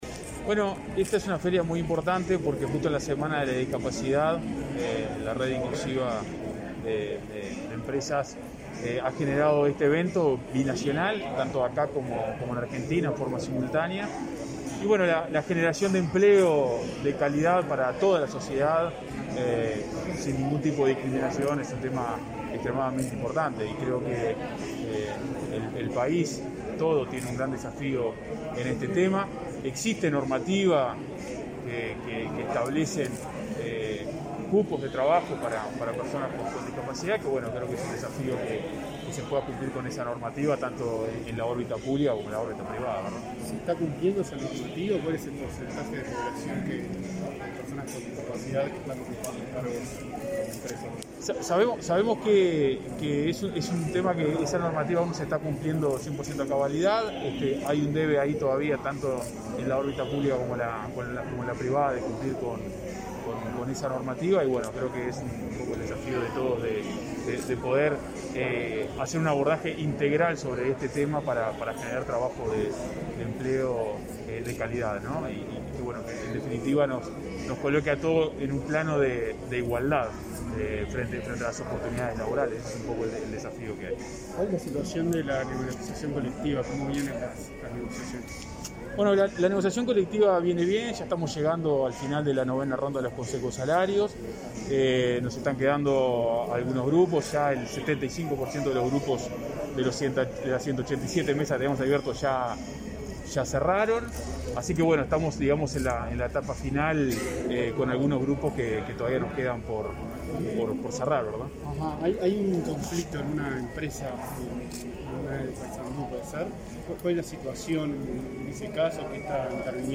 Declaraciones a la prensa del director nacional de Trabajo
El director nacional de Trabajo, Federico Daverede, participó este miércoles 1.° en la inauguración de la primera feria de empleo binacional para